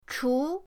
chu2.mp3